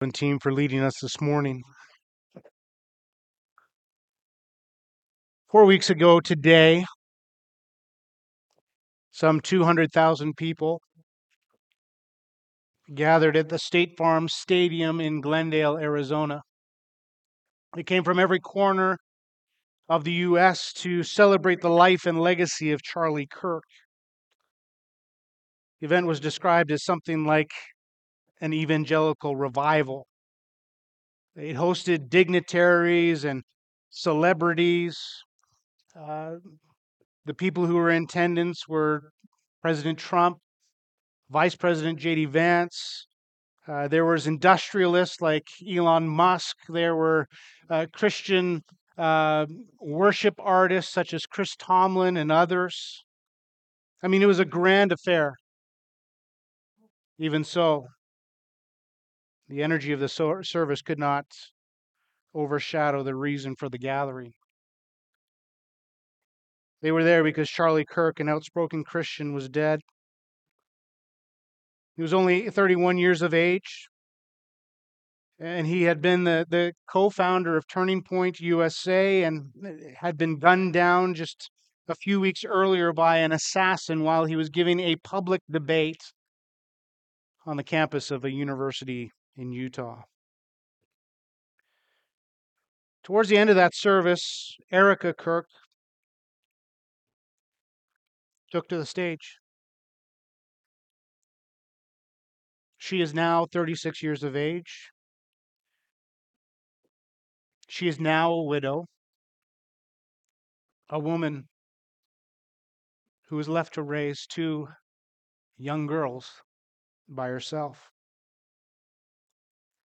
Sunday Sermon Series